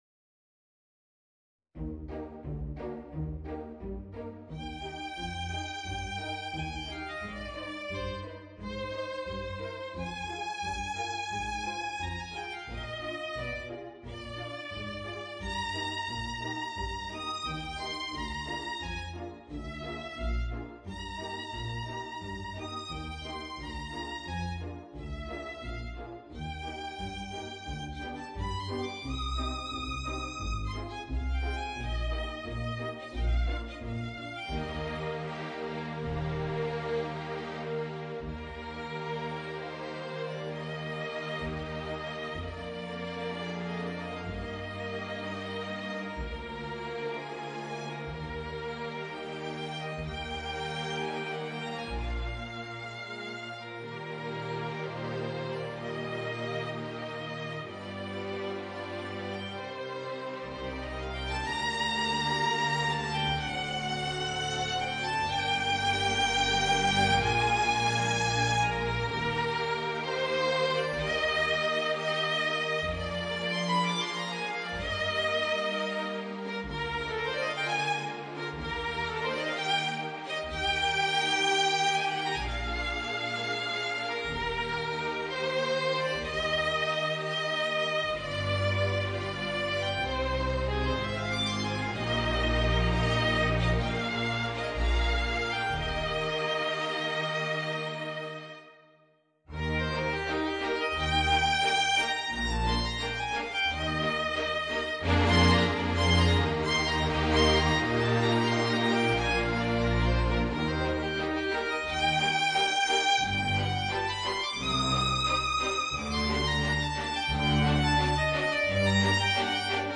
Voicing: Flute and String Orchestra